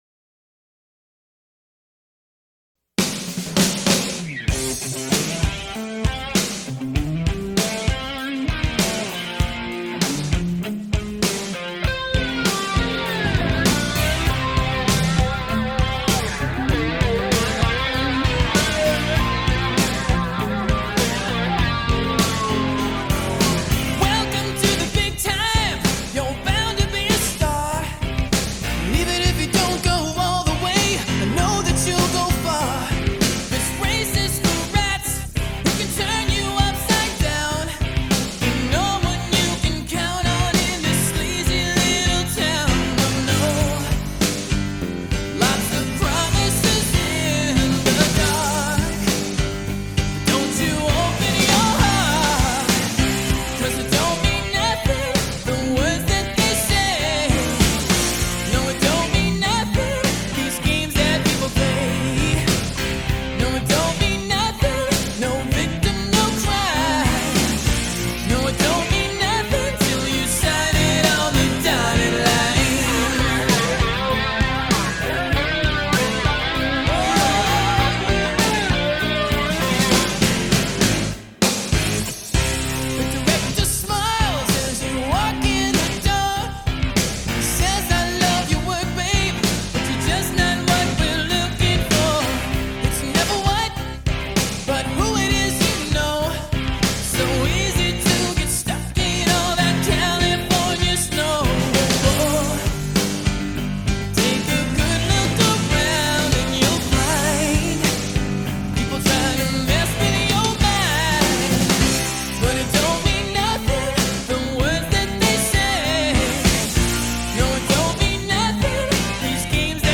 Pop Rock, Ballad